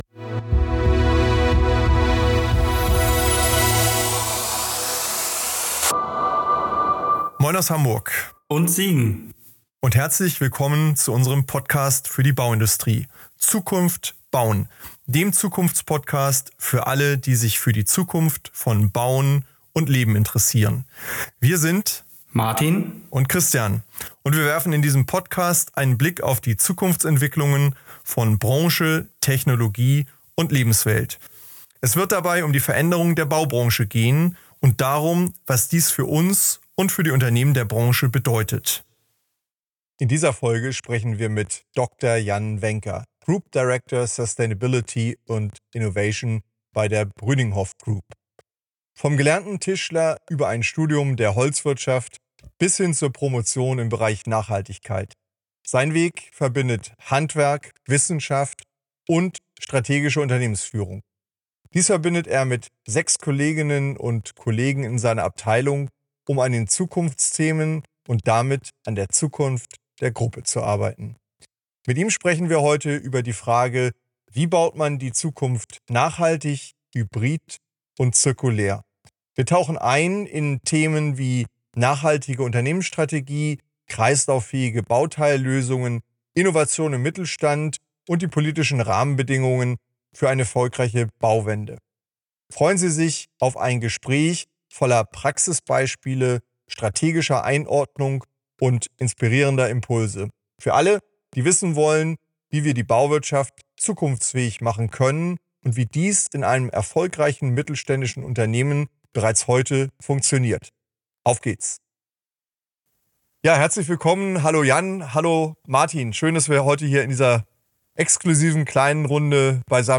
Wir tauchen ein in Themen wie nachhaltige Unternehmensstrategie, kreislauffähige Bauteillösungen, Innovation im Mittelstand und die politischen Rahmenbedingungen für eine erfolgreiche Bauwende. Freuen Sie sich auf ein Gespräch voller Praxisbeispiele, strategischer Einordnung und inspirierender Impulse – für alle, die wissen wollen, wie wir die Bauwirtschaft zukunftsfähig machen können und wie dies in einem erfolgreichen mittelständischen Unternehmen bereits funktioniert.